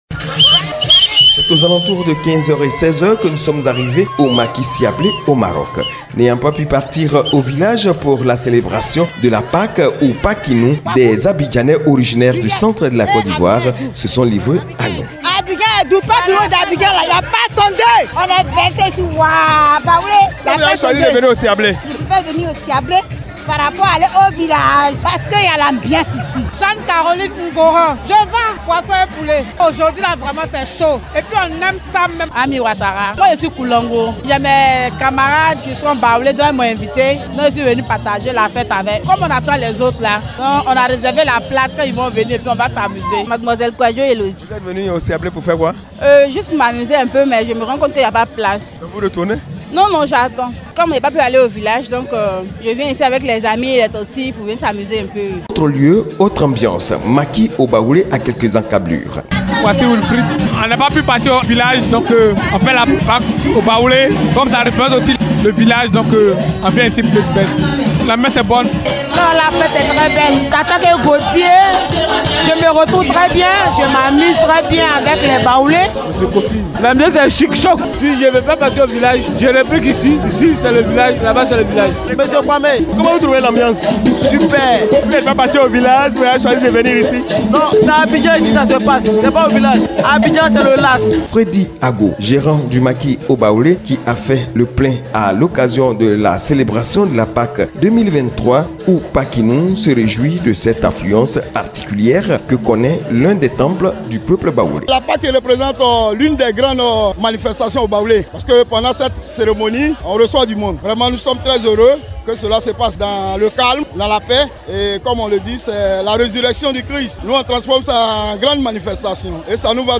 9 avril dans les maquis dédiés à Yopougon-Maroc que sont les maquis O’Baoulé et Siablé.